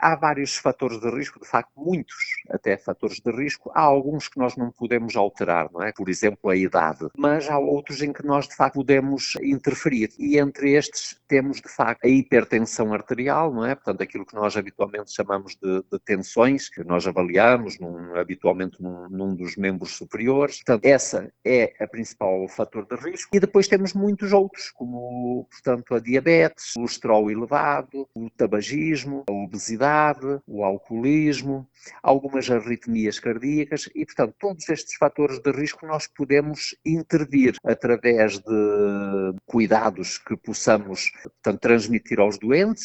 O médico sublinha ainda os principais fatores de risco associados ao AVC: